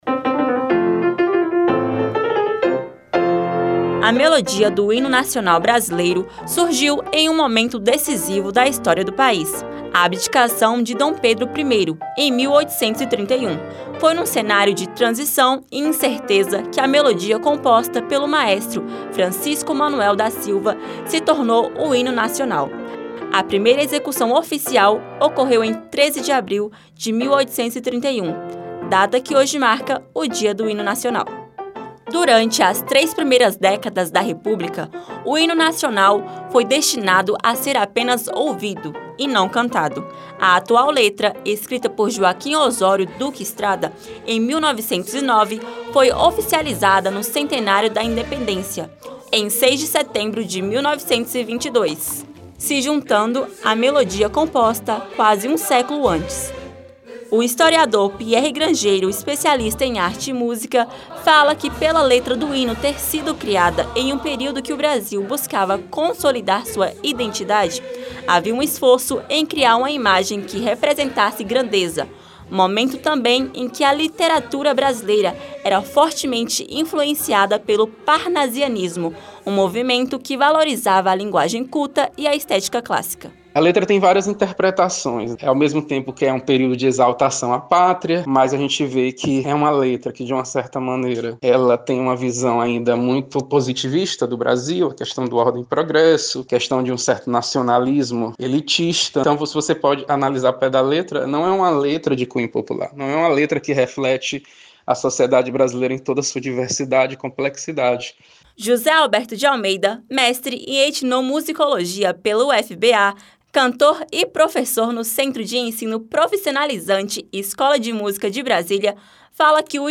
Rádio Senado : Notícias.